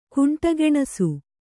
♪ kuṇṭa geṇasu